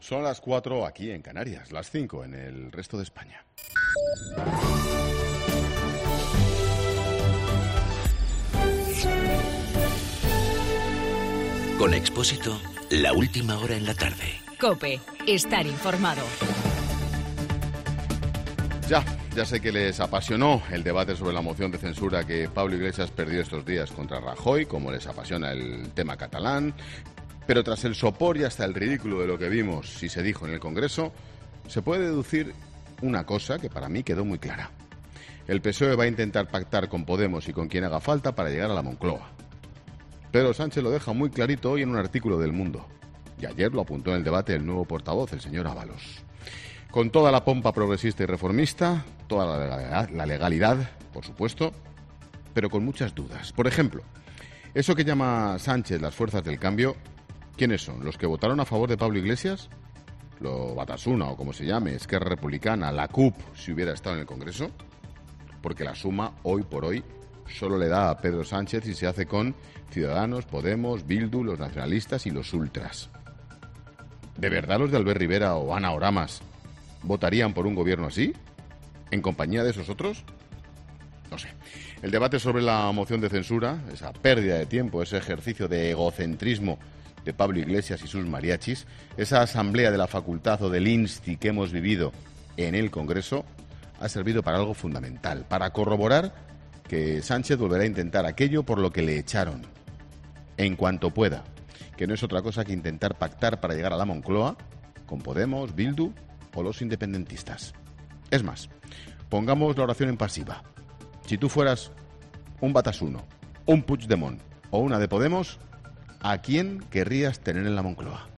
Monólogo de Expósito
El monólogo de Ángel Expósito a las 17h, desde Santa Cruz de Tenerife, un día después de la moción de censura a Rajoy organizada por Podemos.